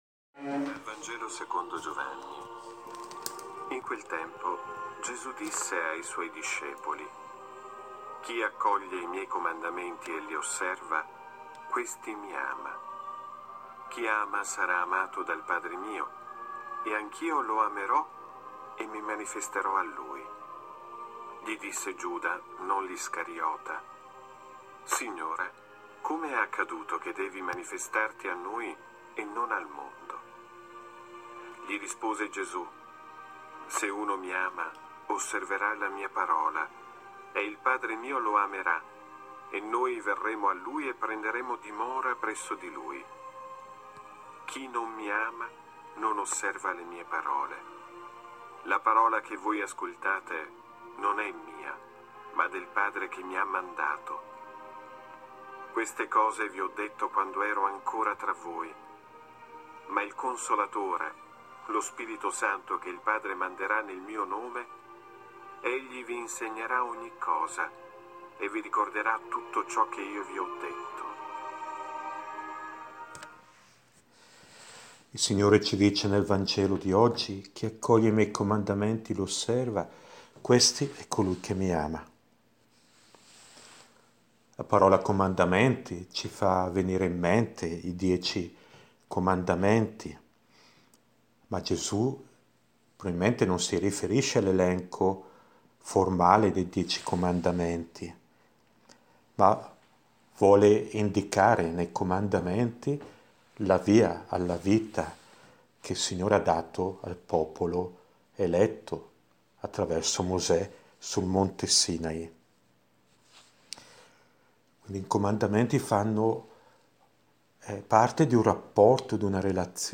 riflessione